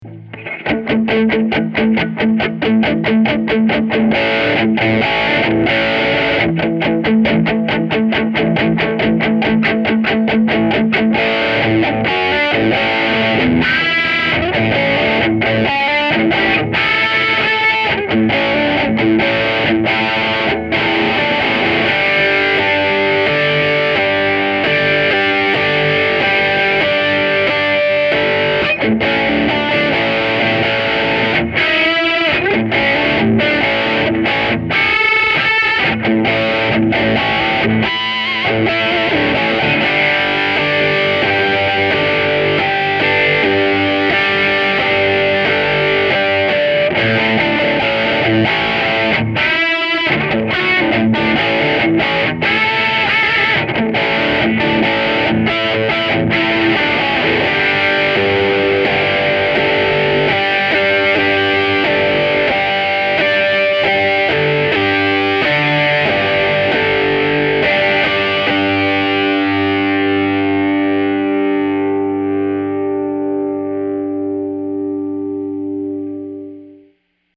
Here are 12 quick, 1-take MP3 sound files of myself playing this guitar to give you an idea of what to expect. The guitar has great tone, sustain, and easy playability, and also excellent tonal variety. The guitar is miced using a vintage Neuman U87 mic on a Peavey Studio Pro 112 amp, straight into a Sony PCM D1 flash recorder, and MP3s were made in Logic.